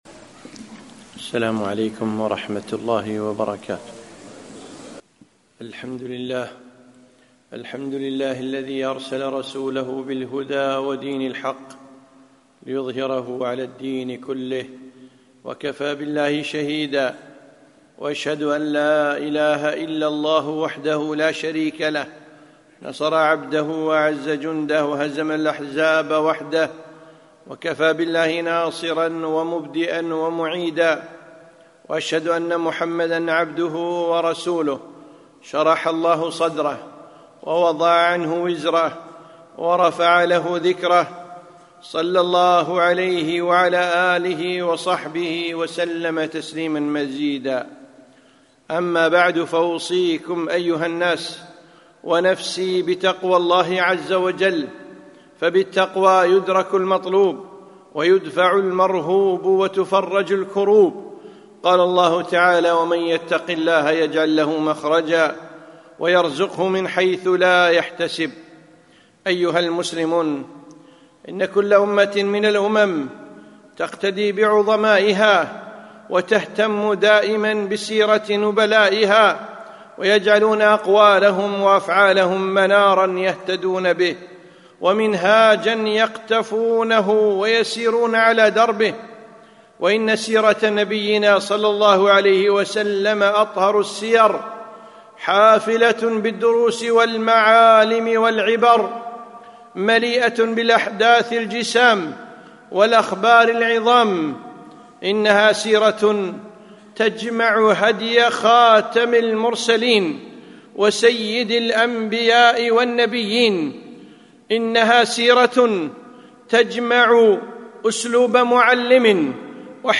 خطبة - الهجرية دروس وعبر